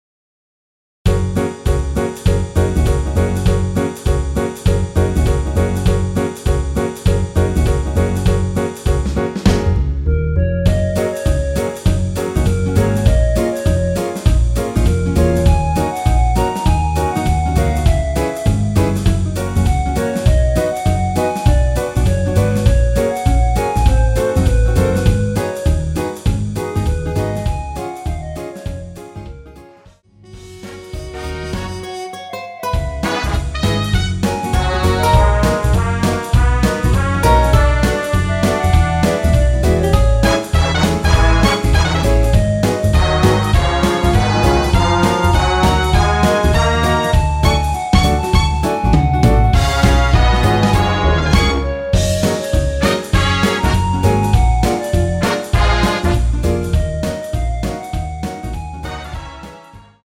여자키 멜로디 MR 입니다.
대부분의 여성분이 부르실수 있는키로 제작 하였습니다.(미리듣기 참조)
F#
앞부분30초, 뒷부분30초씩 편집해서 올려 드리고 있습니다.
(멜로디 MR)은 가이드 멜로디가 포함된 MR 입니다.